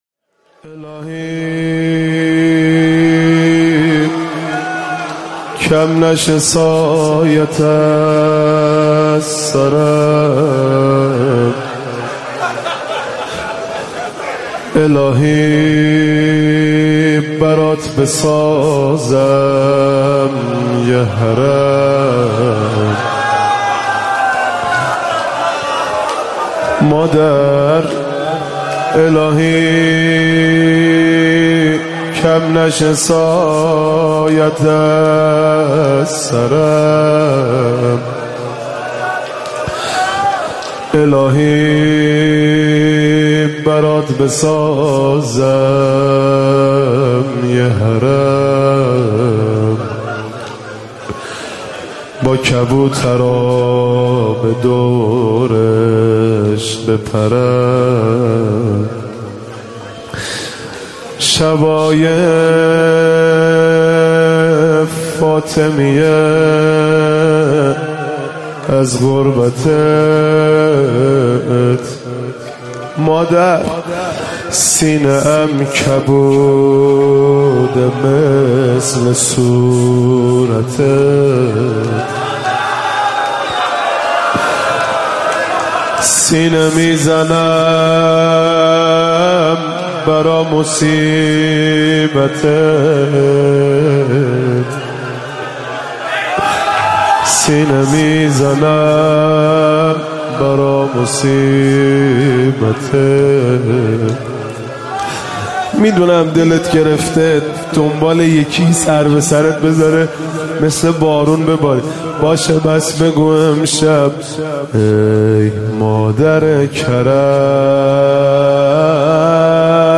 مداحی جدید
roze